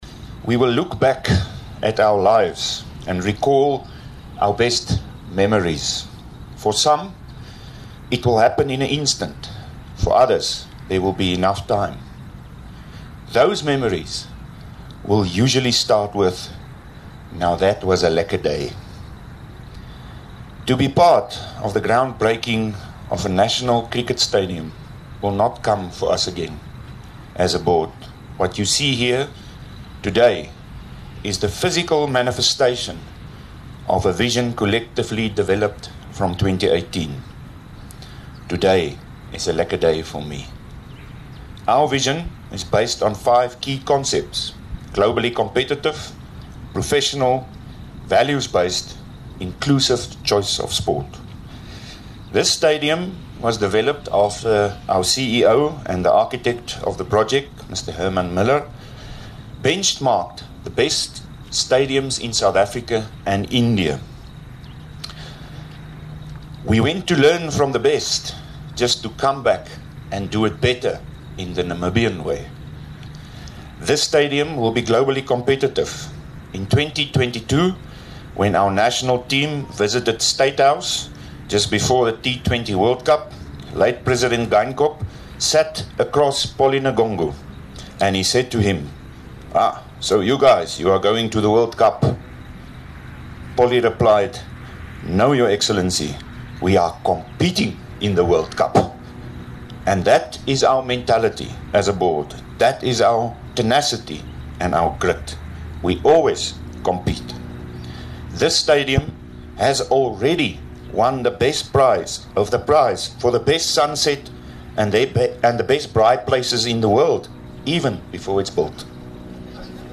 20 Mar Dr Rudi van Vuuren speaks at the National Cricket Stadium groundbreaking
The President of Cricket Namibia, Dr Rudi van Vuuren. spoke at the official groundbreaking ceremony for the new national cricket stadium of Namibia.